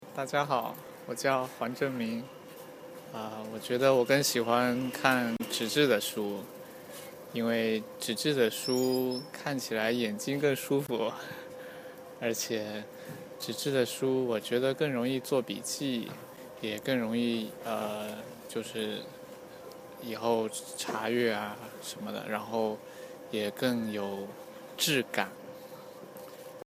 - Absolut authentische Audiobeiträge von Beijings Straßen!
Wir sind mit unserem Aufnahmegerät losgezogen und haben Stimmen in Beijing eingefangen, sie frisch eingetütet und in unsere NIU ZHONGWEN Wundertüte gepackt!
Wir wollten es wissen und haben auf Beijings Straßen nachgefragt.